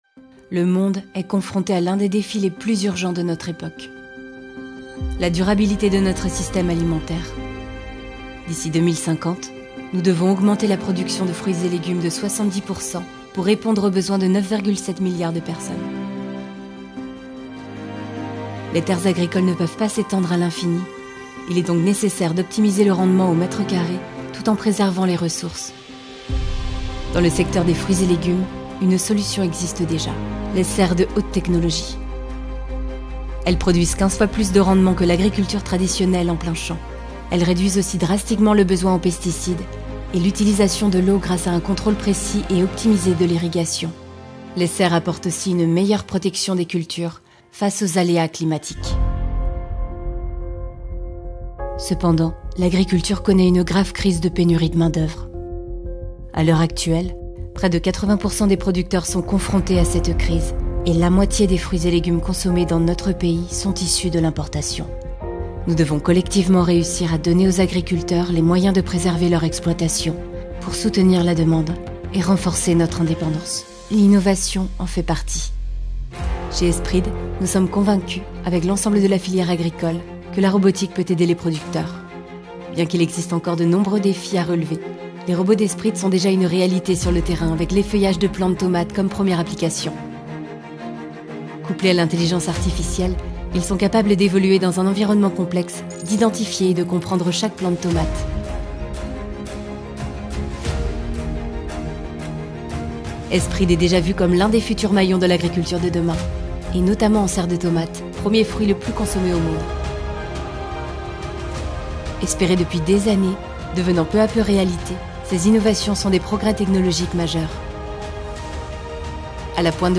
Jeune, Naturelle, Douce, Chaude, Commerciale
Corporate
Avec une voix moyenne, chaude et fluide, je donne vie à vos projets grâce à des narrations authentiques, captivantes et empreintes de sincérité.
Mon studio d’enregistrement professionnel garantit une qualité sonore optimale pour vos productions.